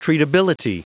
Prononciation du mot treatability en anglais (fichier audio)
Prononciation du mot : treatability